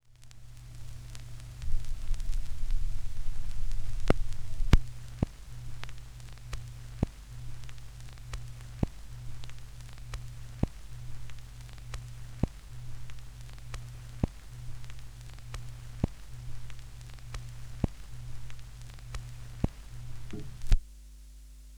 Je vous offre volontiers mes enregistrements de craquements de vinyle "bien vécu" que j'ai réalisés sur une Thorens TD 128 MK II... icon_mdr.gif
Les fichiers audio sont au format Aiff Stéréo 16 bit 44'100 Hz, perso je les assemble en réalisant des fondus croisés...
Fin de vinyl
Vinyl end.aif